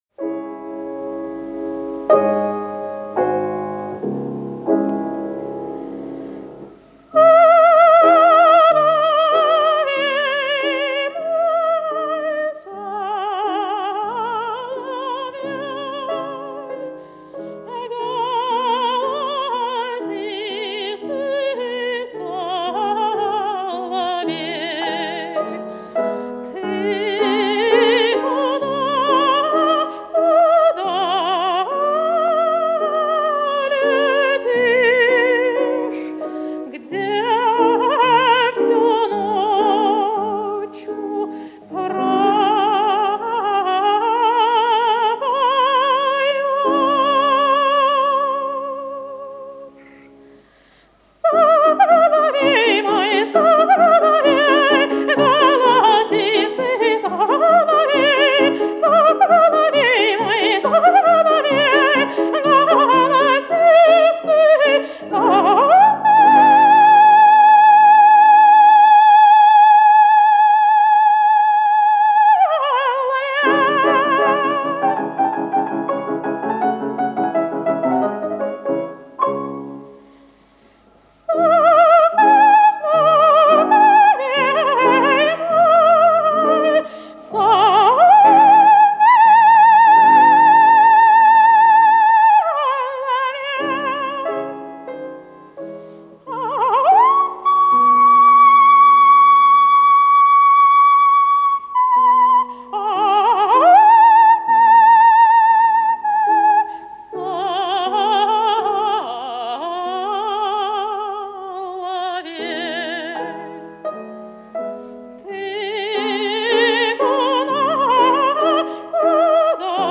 Во Всесоюзный Радикомитет Казанцеву приняли по конкурсу на амплуа лирико-колоратурного сопрано. Более 20 лет звучал в эфире её нежный, лёгкий, кристально чистый голос.